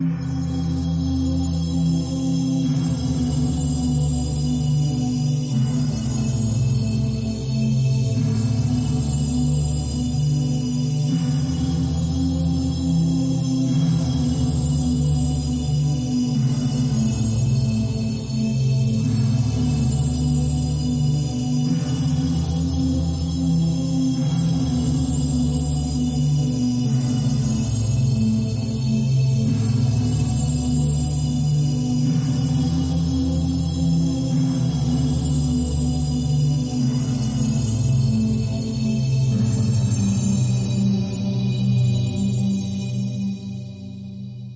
现场太空垫07
描述：活Krystal Cosmic Pads